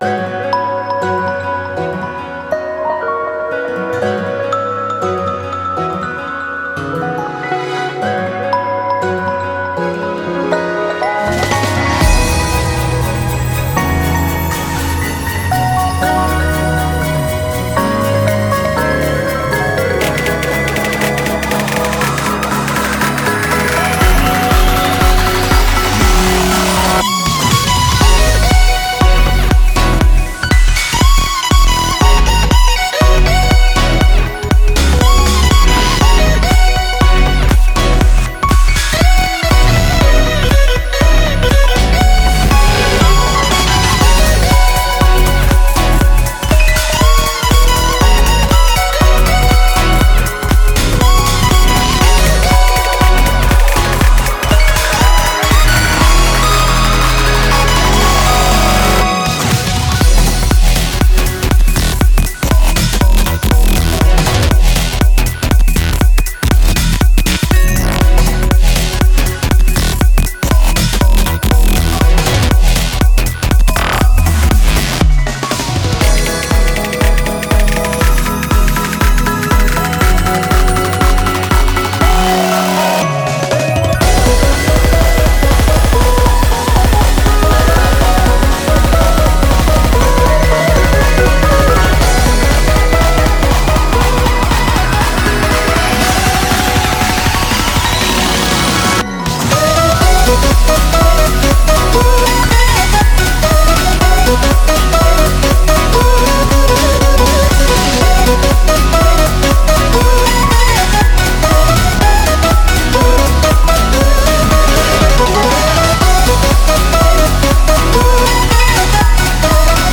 BPM120
Audio QualityPerfect (High Quality)